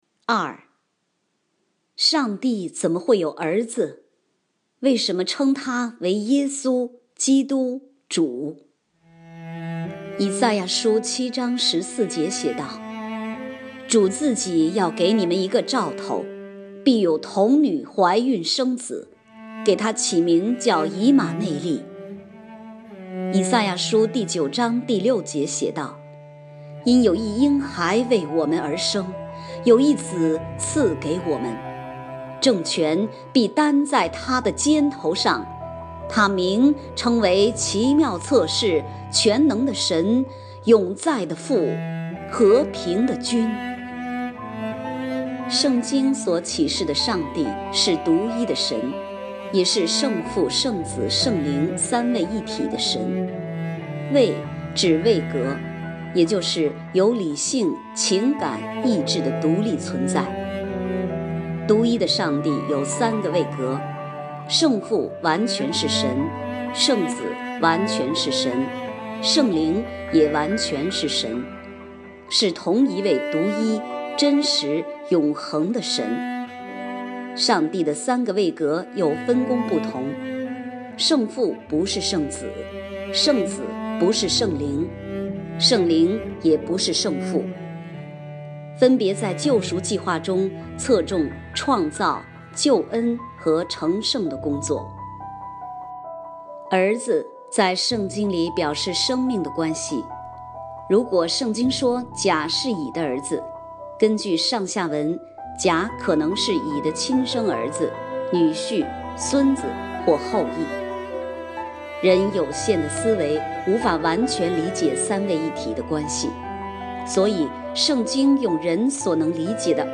（配乐：以马内利来临 O come, O come, Emmanuel）